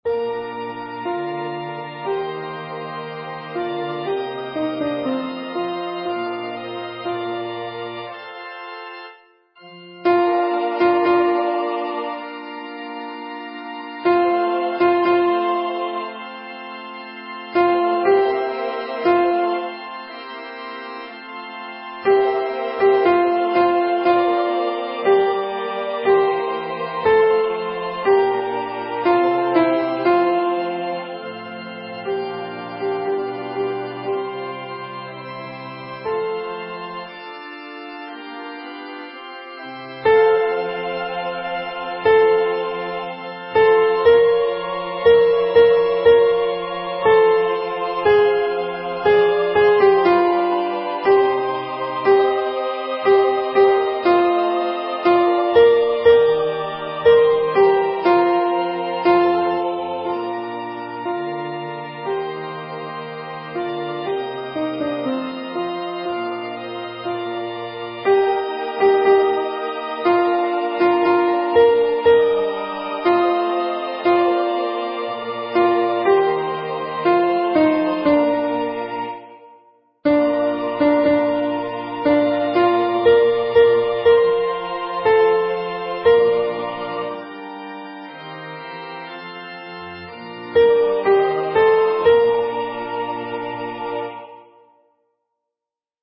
Number of voices: 4vv   Voicing: SATB
Genre: SacredMass
Instruments: Organ
HaydnMassConcKyrieAltoP.mp3